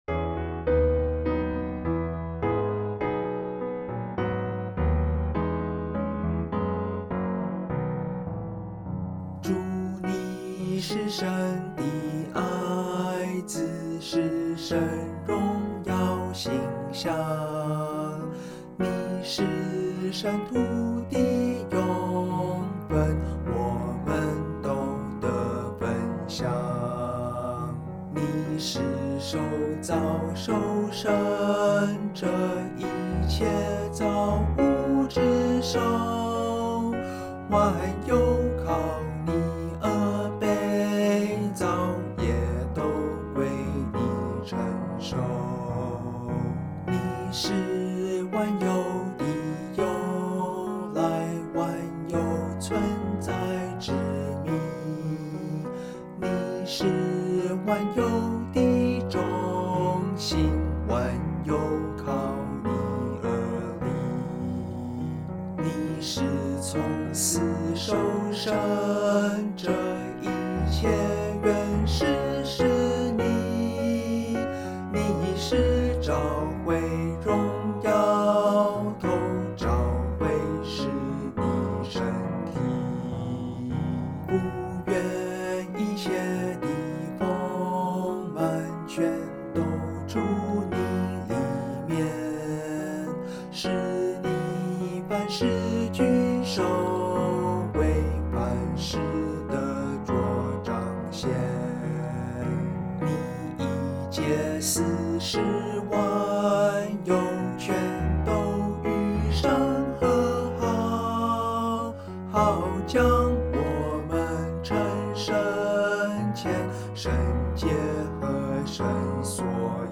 Db Majeur